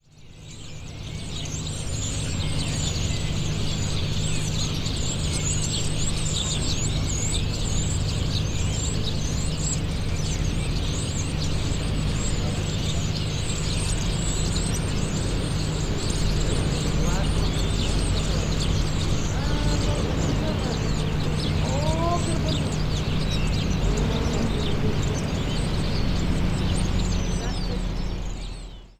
ambience
Dock Ambience - Birds, Voices